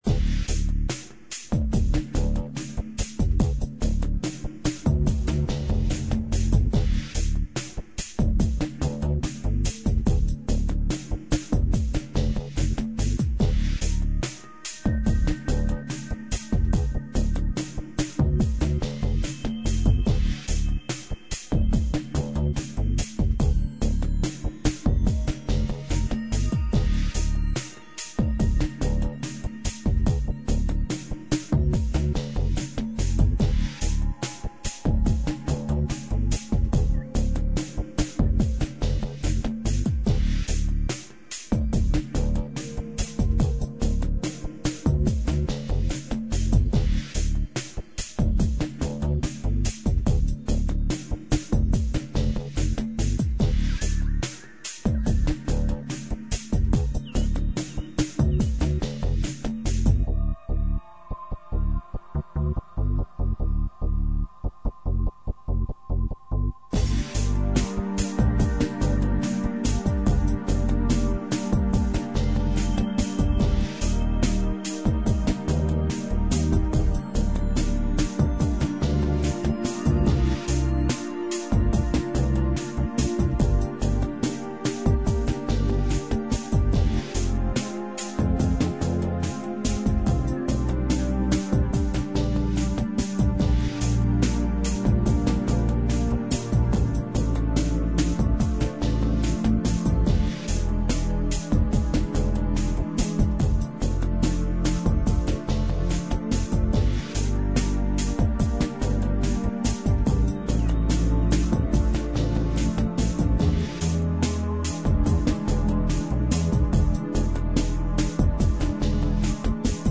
dance/electronic
TD style chords and erratic bass line.
Ambient